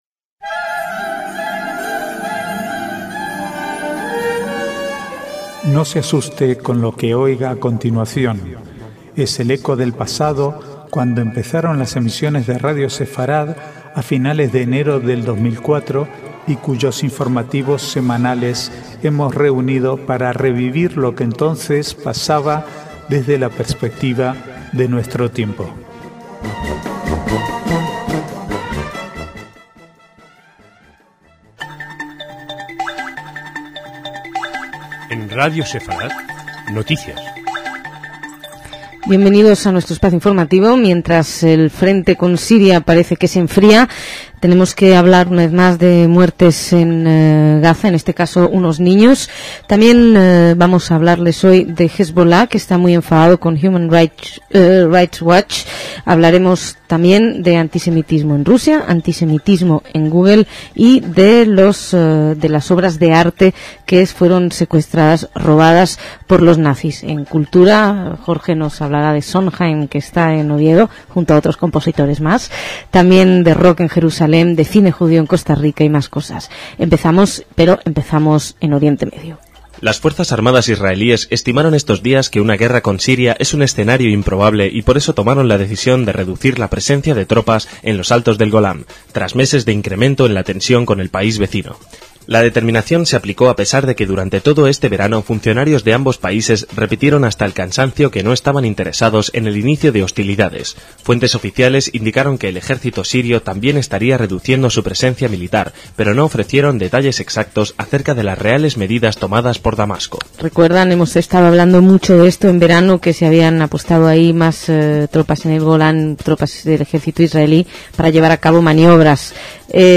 Archivo de noticias del 30/8 al 4/9/2007